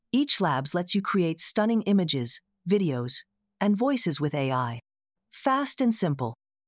Kling V1 | Text to Speech - AI Model | Harmony AI
Genere locuciones naturales y profesionales a partir de texto con voces diversas, velocidad ajustable y salida MP3 multilingüe.
kling-video-v1-tts-output.mp3